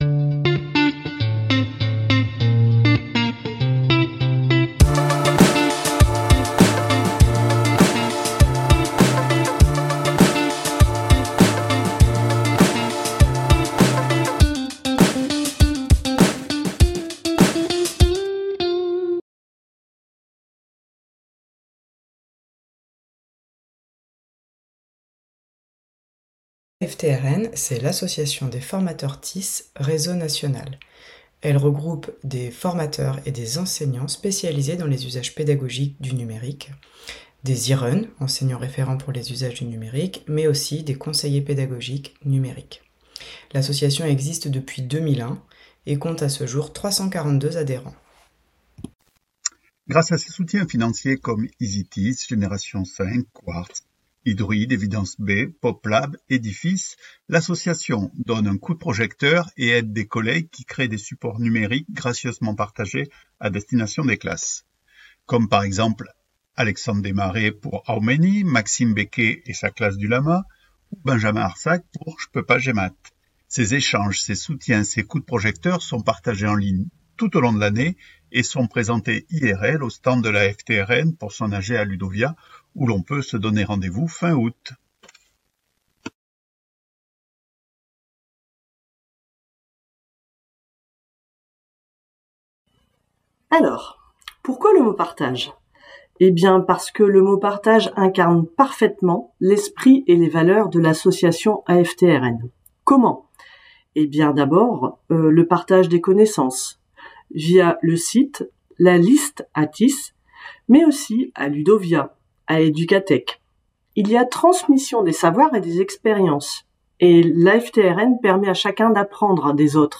Rencontre inspirante avec les membres de l’association AFT-RN, en trois parties :
ITW – Association AFT-RNTélécharger